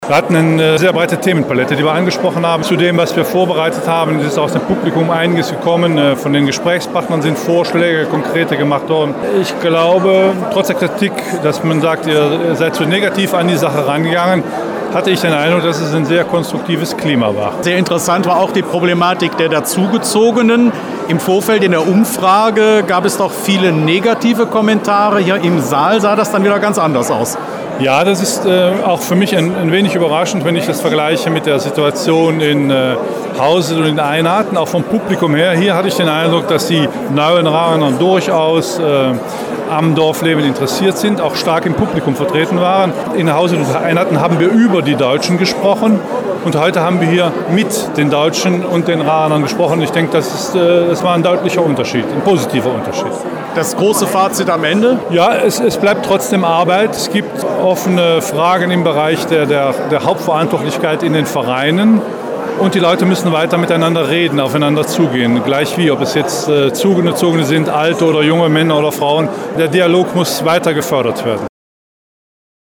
Am Sonntag fand im Bergscheiderhof in Raeren eine weitere LokalRunde des GrenzEcho statt.